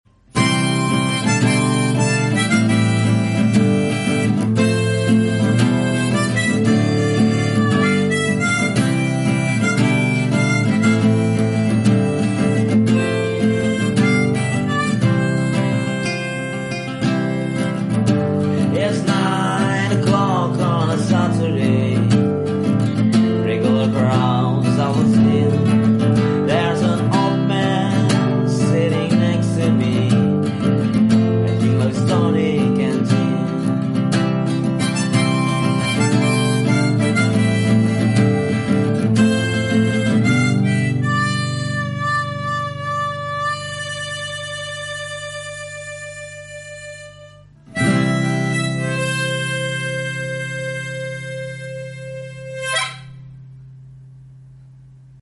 Versi Gitar Harmonika